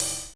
Drums14C.wav